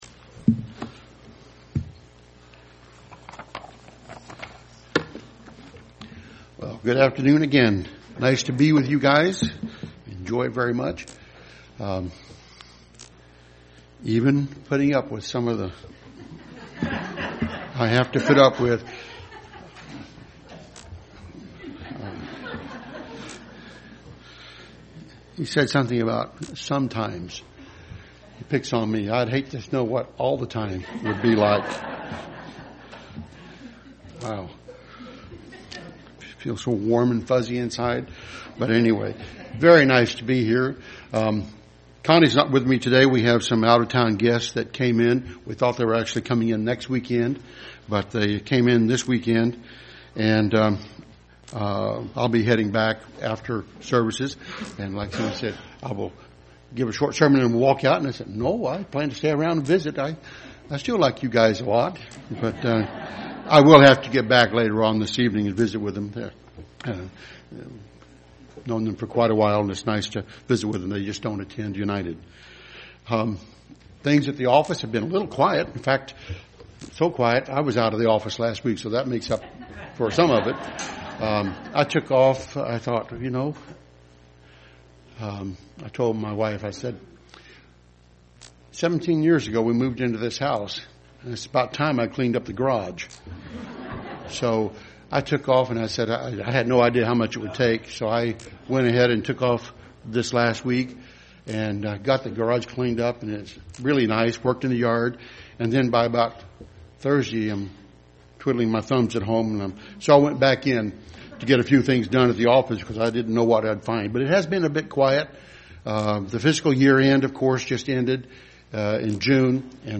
UCG Sermon Studying the bible?
Given in Dayton, OH